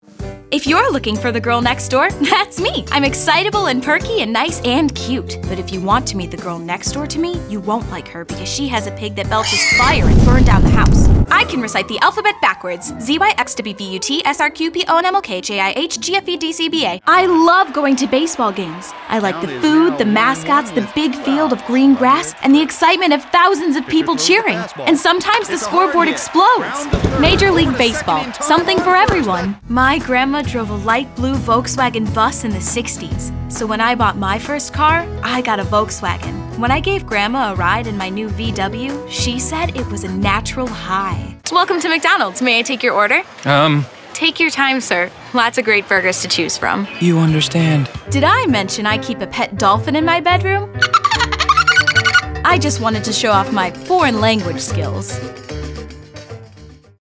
Voiceover : Commercial : Women